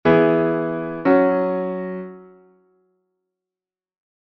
Cadencias Suspensivas
Cadencia Rota V-VI
MI-SI-MI-SOL; FA-LA-DO-FA